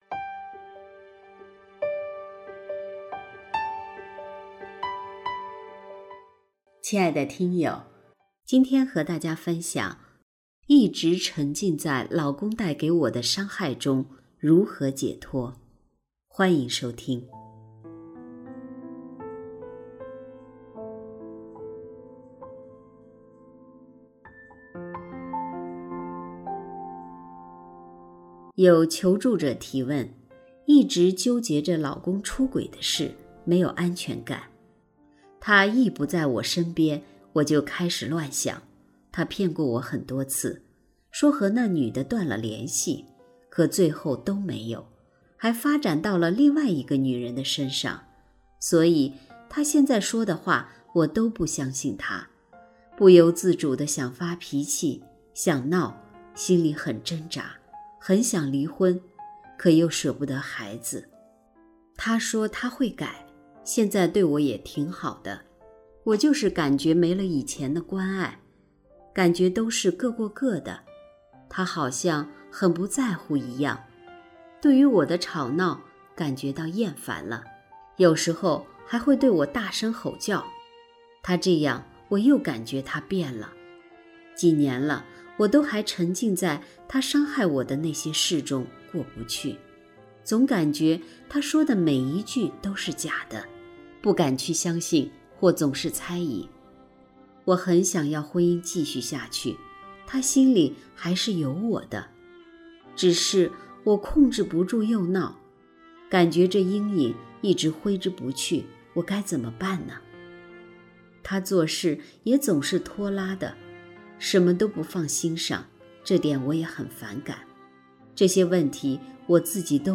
首页 > 有声书 > 婚姻家庭 > 单篇集锦 | 婚姻家庭 | 有声书 > 一直沉浸在老公的伤害中